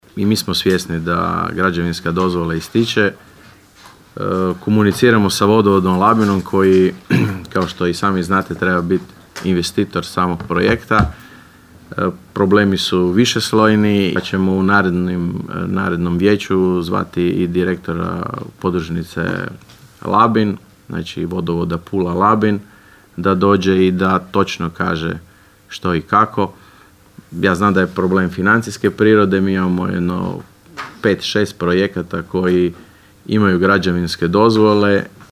Na sjednici Općinskog vijeća Raše vijećnica Orieta Jelčić pitala je što je s izgradnjom cjevovoda Brgod–Macarini–Lončari–Stonje, za koji uskoro istječe građevinska dozvola. Na pitanje je odgovorio načelnik Leo Knapić.
ton – Leo Knapić) – odgovorio je općinski načelnik Leo Knapić.